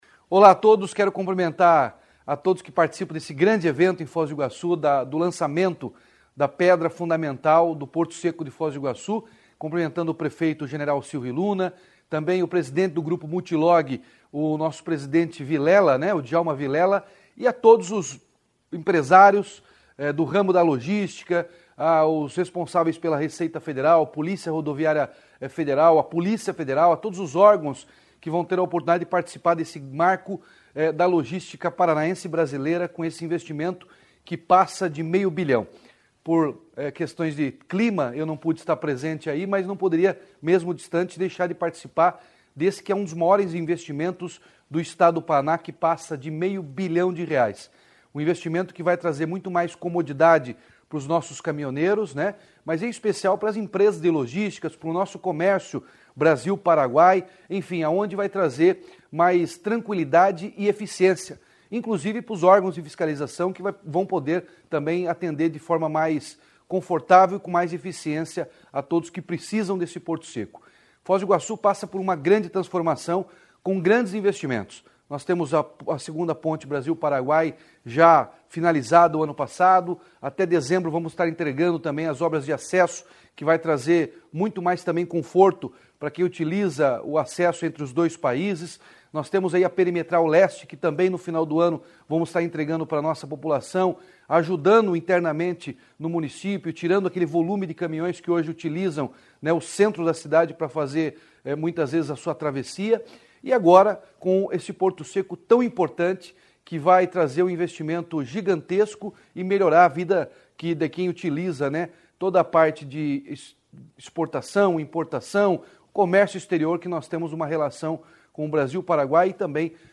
Sonora do governador Ratinho Junior sobre o novo Porto Seco de Foz do Iguaçu